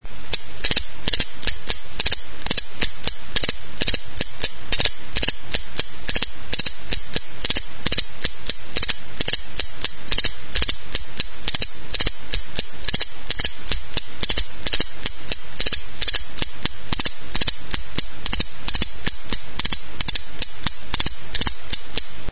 Idiophones -> Struck -> Indirectly
Erakusketa; hots-jostailuak
Intxaur azal-erdi bat da.